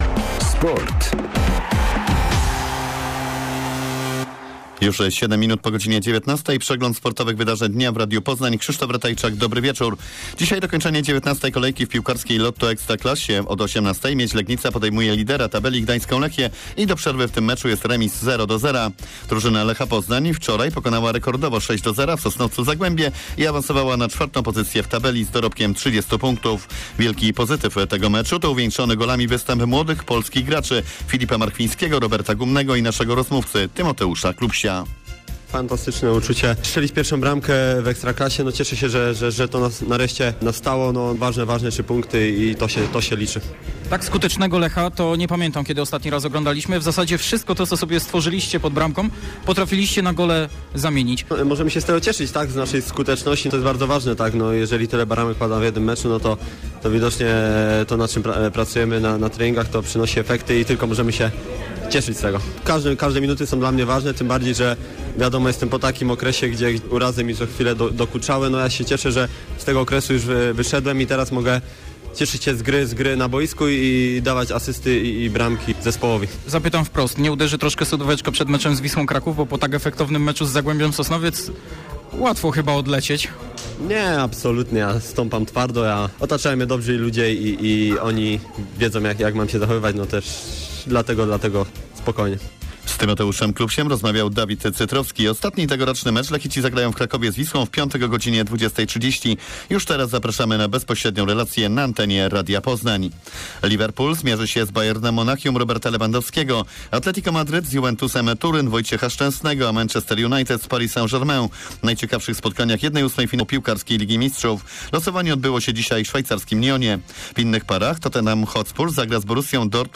17.12. serwis sportowy godz. 19:05